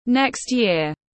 Năm sau tiếng anh gọi là next year, phiên âm tiếng anh đọc là /nekstˌjɪər/
Next year /nekstˌjɪər/